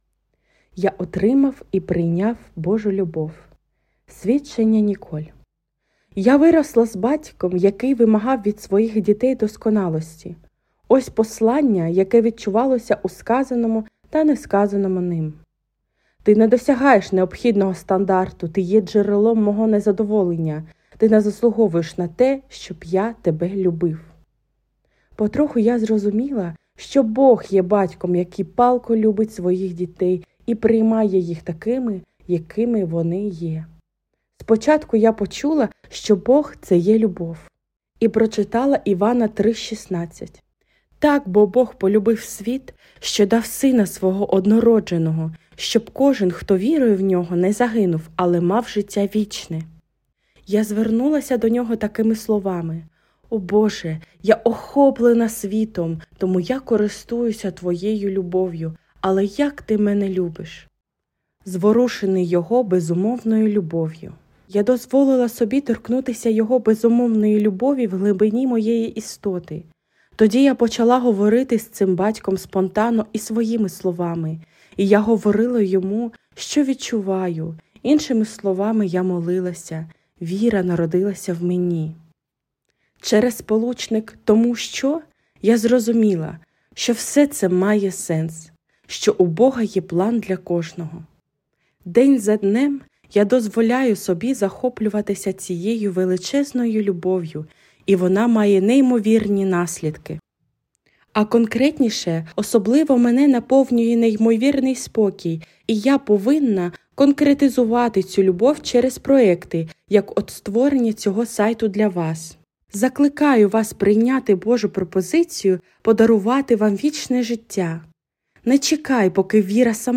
Аудіо, Свідчення